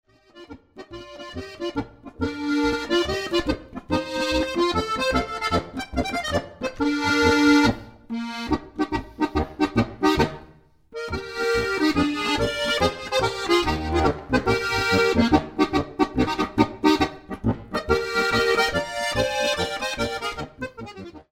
• für 4-reihige Harmonika